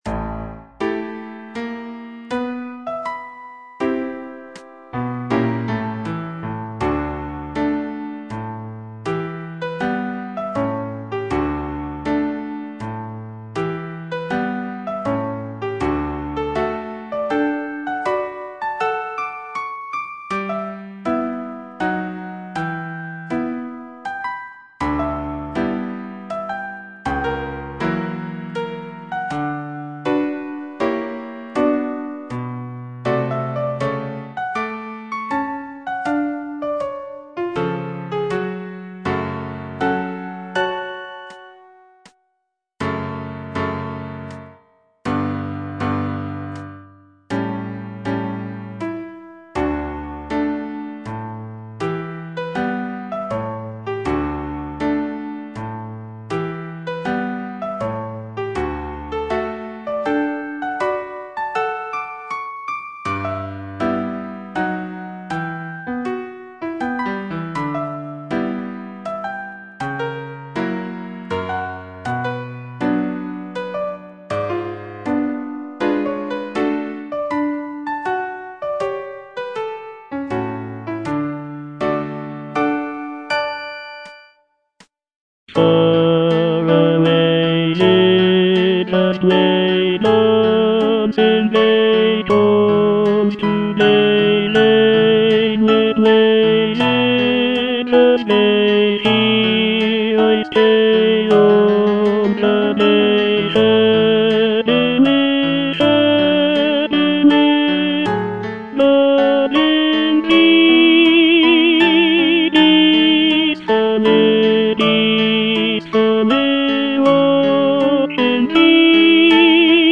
(tenor I) (Voice with metronome) Ads stop